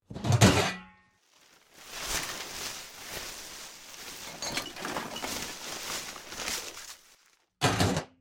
garbage_can_0.ogg